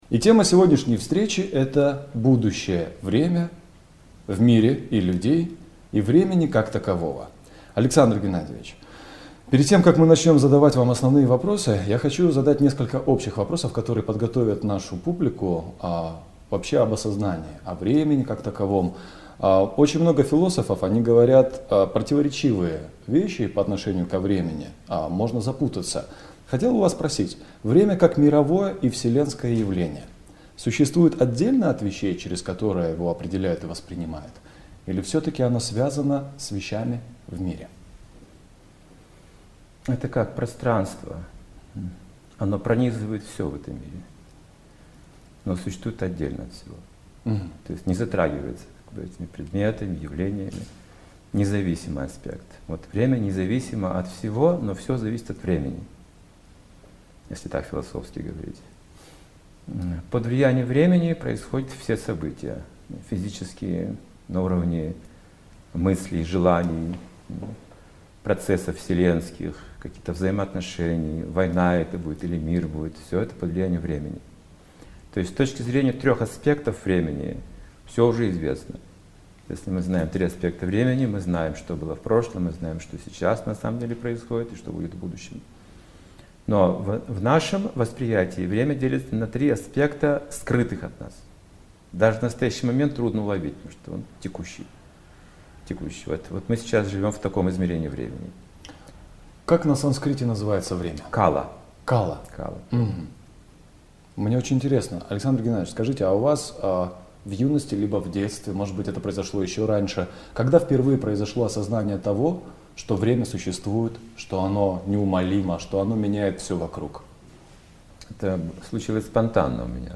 2020.02, Алматы, Интервью в программе "Главные темы в кино", Фильм второй - Будущее время в мире людей